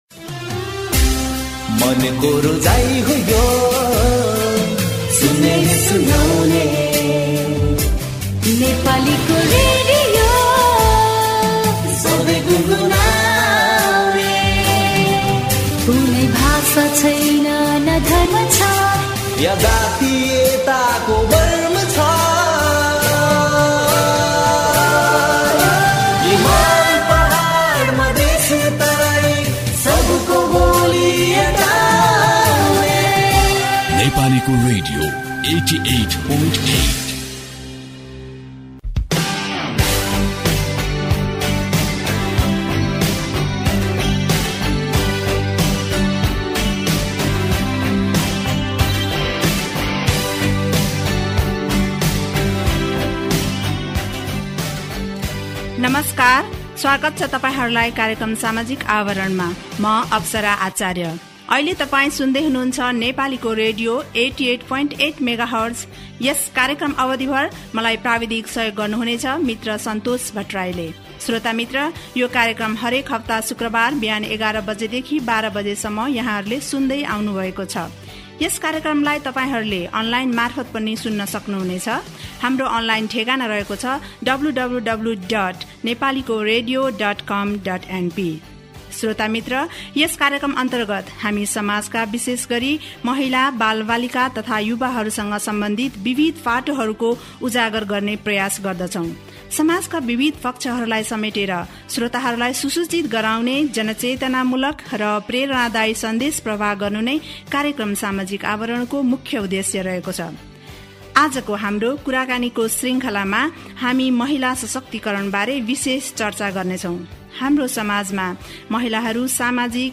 Radio Programme Samajik Awaran